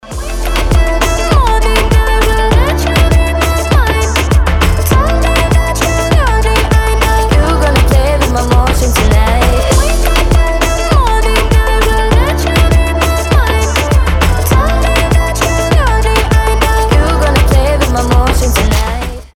• Качество: 320, Stereo
поп
заводные
красивый женский голос